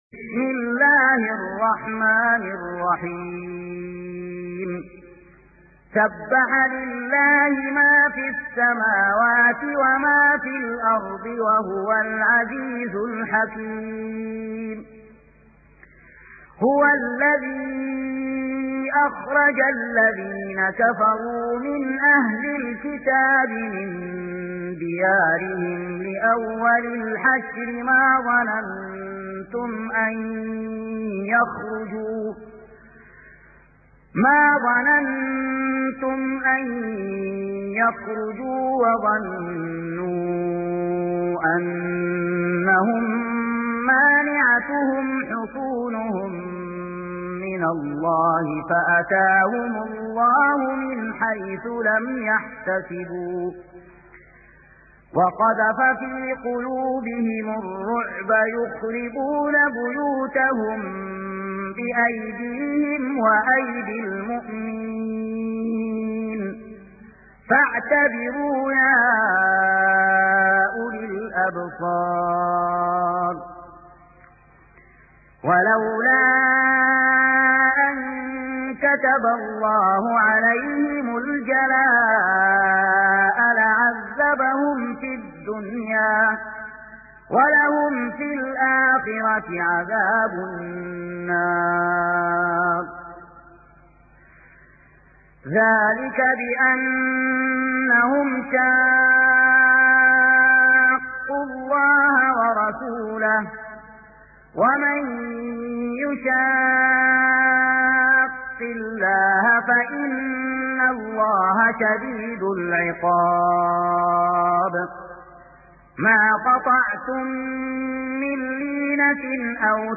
سورة الحشر | القارئ